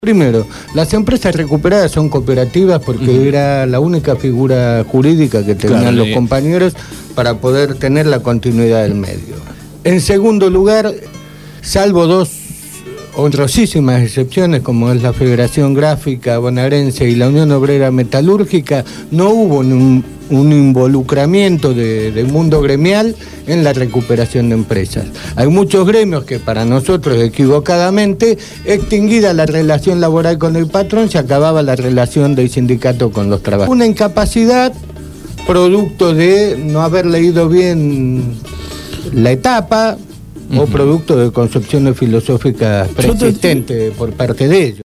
Entrevista
en los estudios de Radio Gráfica FM 89.3 con motivo del encuentro de trabajadores de empresas autogestionadas que se realizado el viernes 14 de octubre en la Cooperativa Gráfica Patricios.